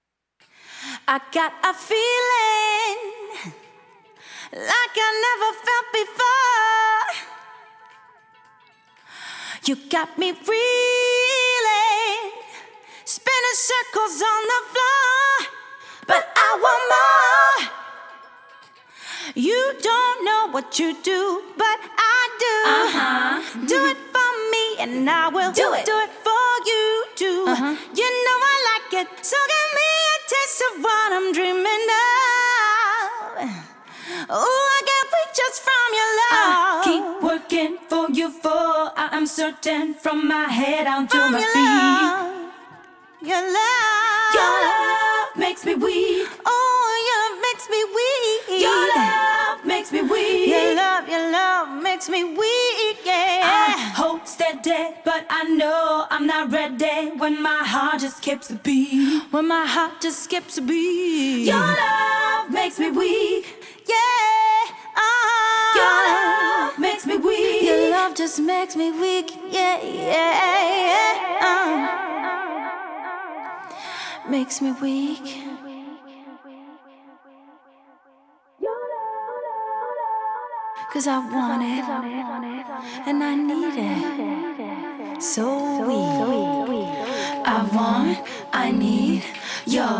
ジャンル(スタイル) DISCO / NU DISCO